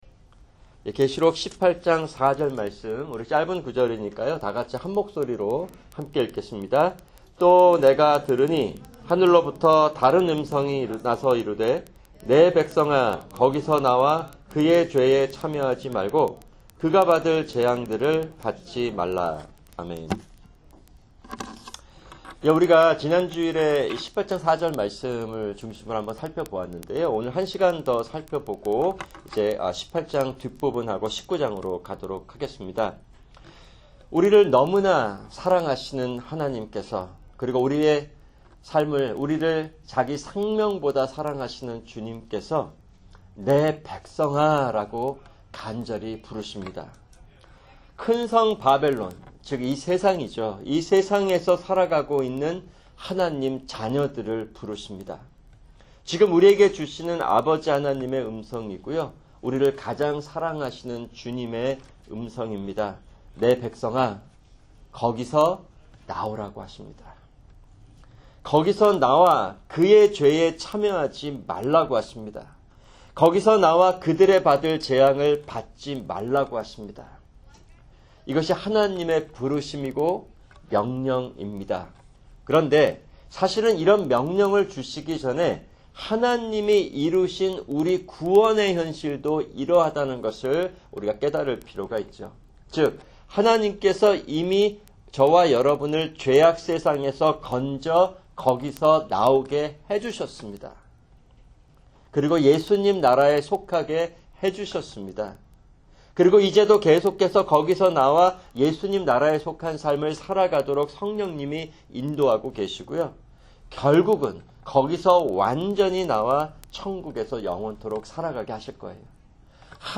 [금요 성경공부] 계시록 18:4(2)